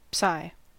Ääntäminen
IPA : /psaɪ/ IPA : /saɪ/